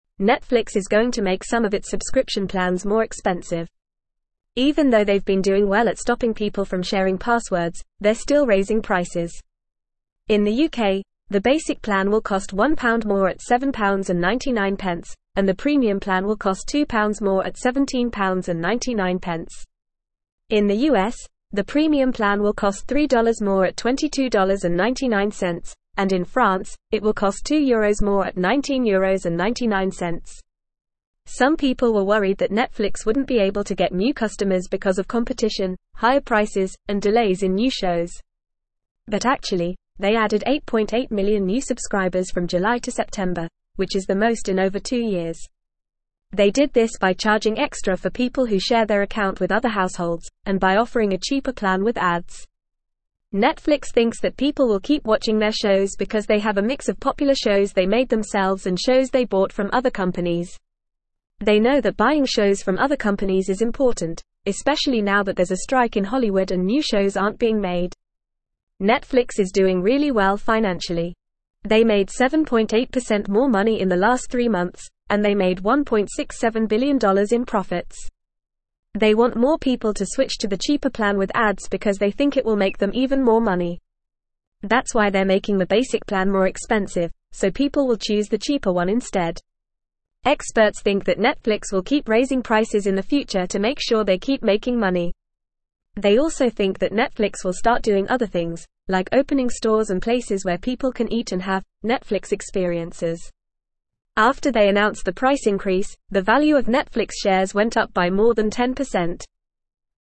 English-Newsroom-Upper-Intermediate-FAST-Reading-Netflix-Raises-Subscription-Prices-Despite-Recent-Success.mp3